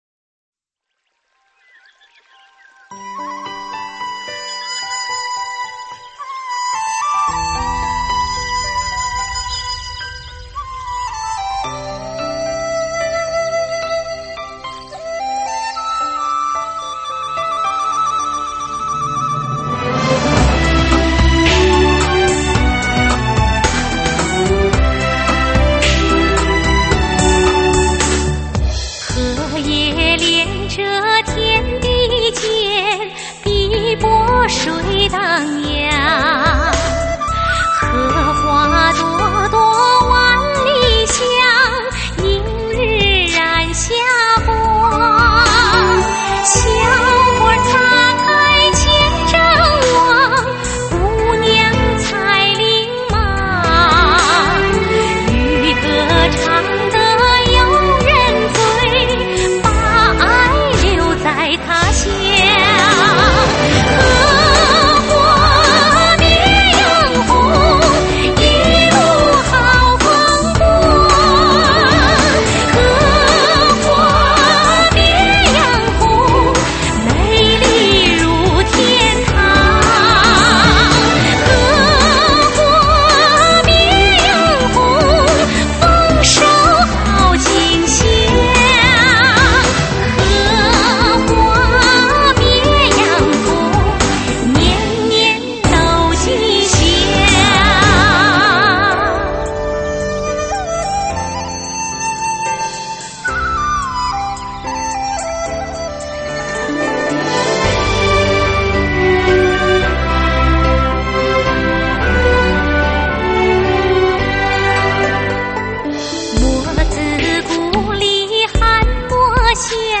【军旅歌手】